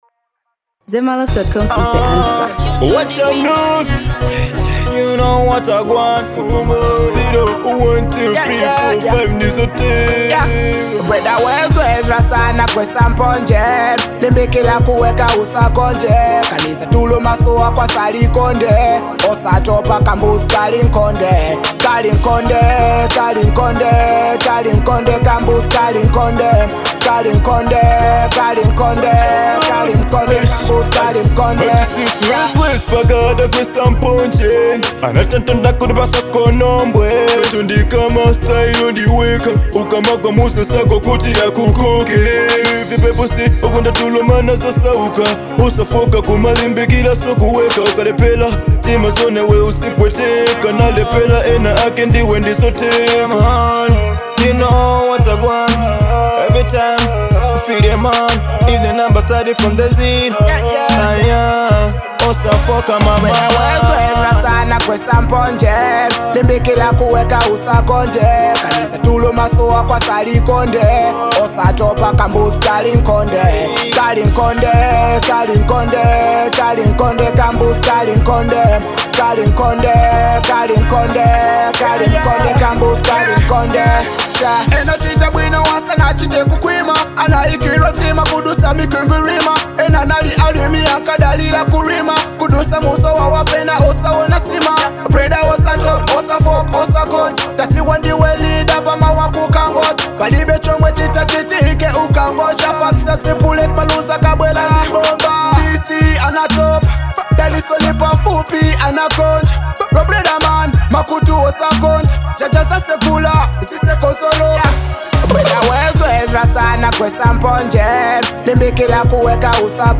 Kwaito|Afrobeats|Amapiano|Dancehall • 2025-07-12